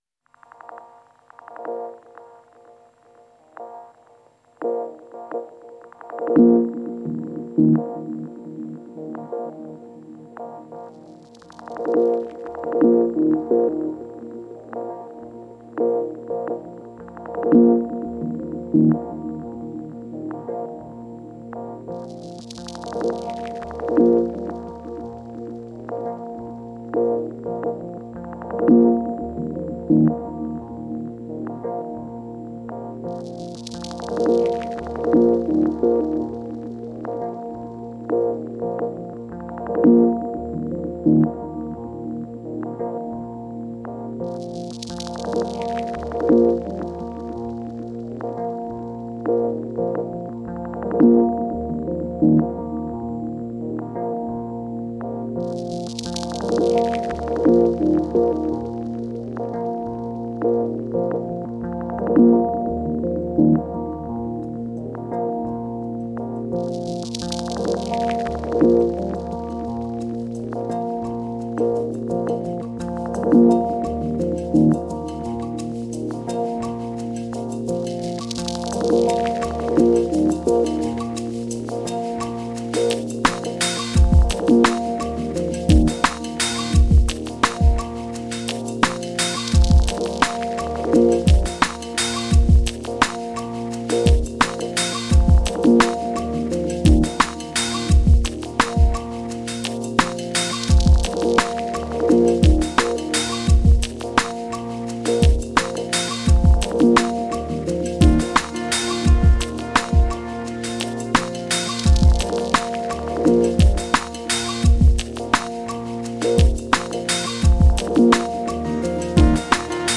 Genre: Downtempo, Ambient.